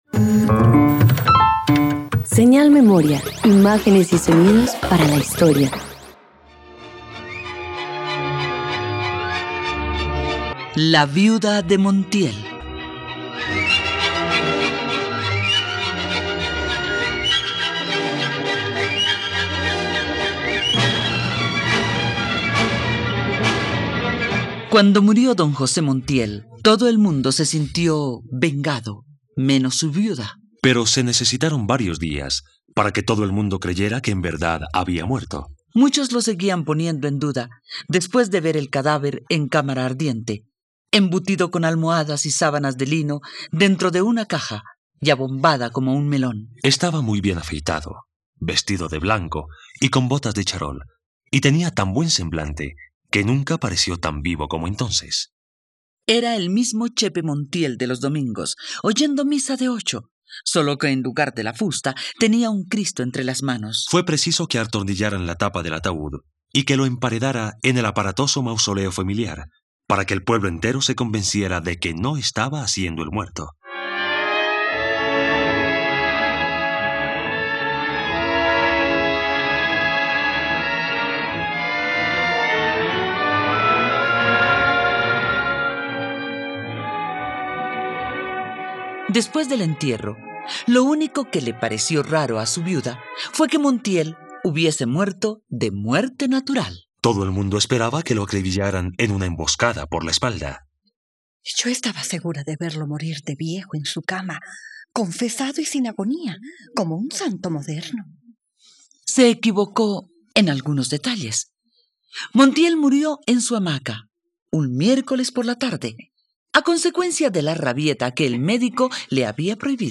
La viuda de Montiel - Radioteatro dominical | RTVCPlay
..Radioteatro. Esta obra del escritor colombiano Gabriel García Márquez narra la historia de la viuda Adelaida Montiel.